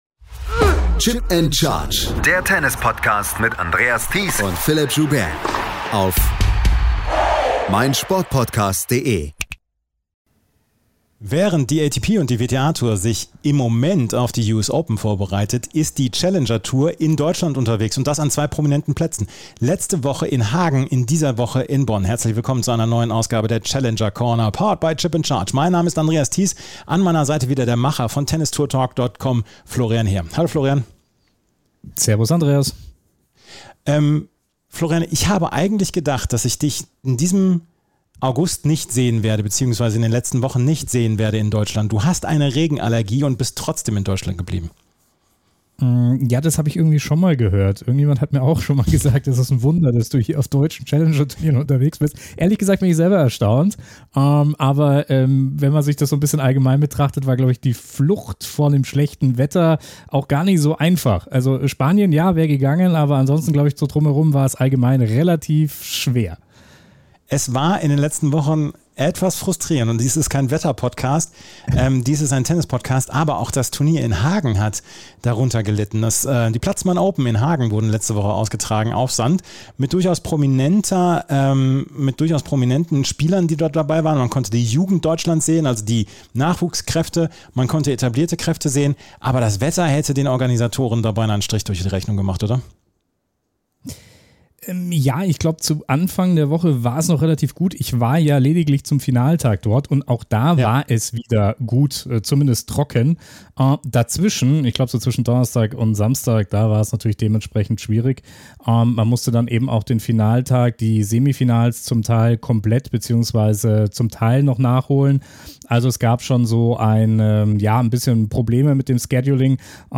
Mitgebracht hat er auch wieder einige Interviews.